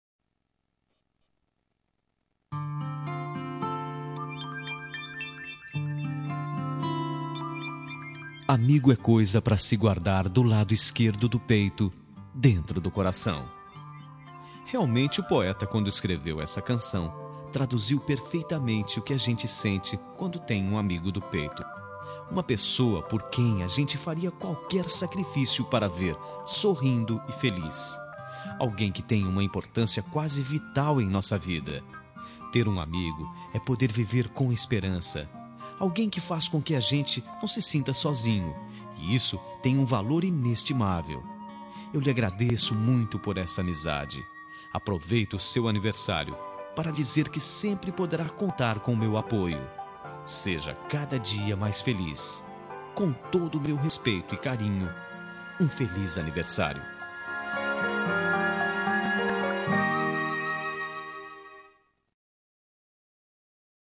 Telemensagem de Aniversário de Amigo – Voz Masculina – Cód: 1622